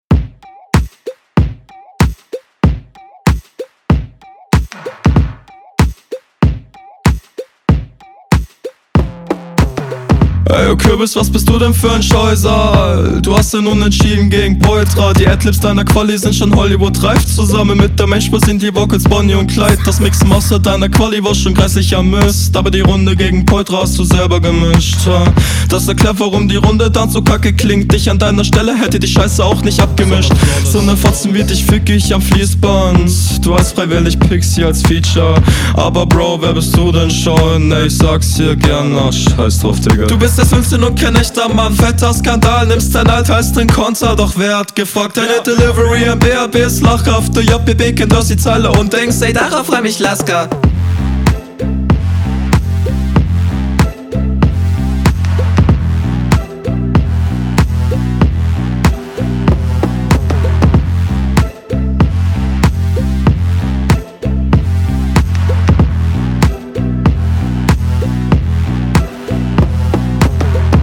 Stimmeneinsatz hat sich hier sehr gewandelt.